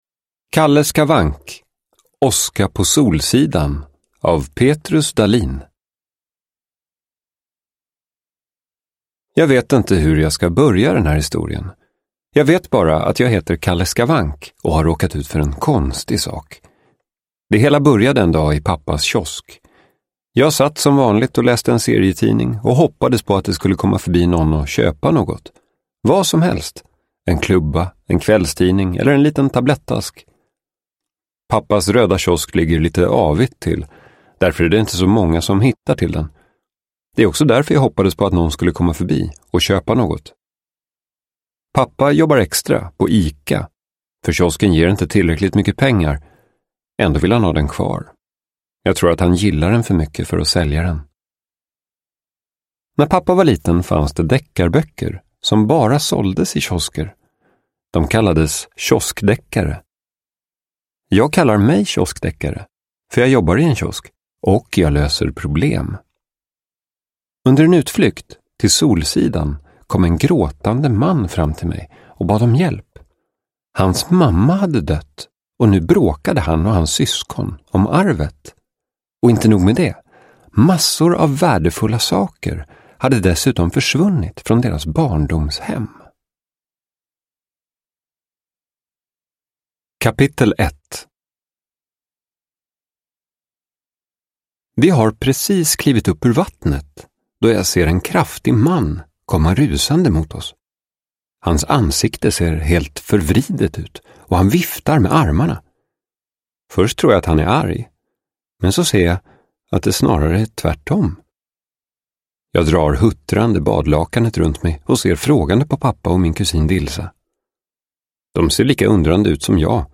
Åska på Solsidan – Ljudbok